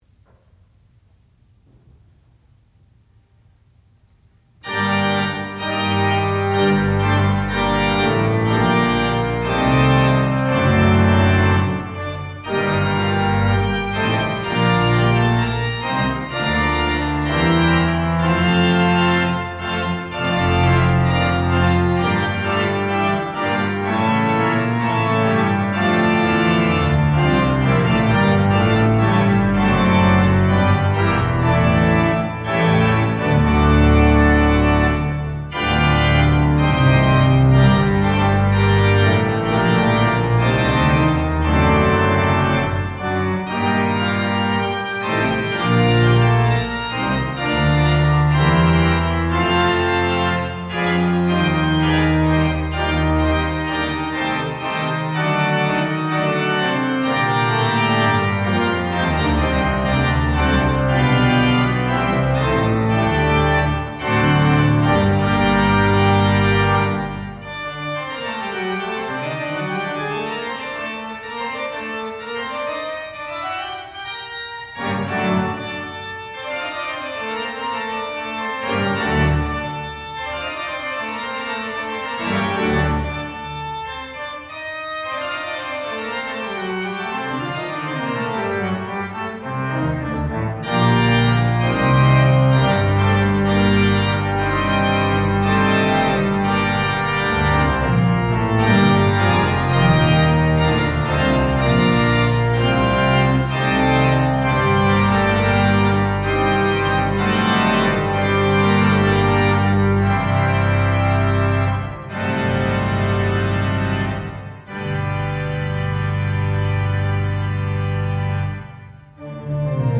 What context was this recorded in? Master's Recital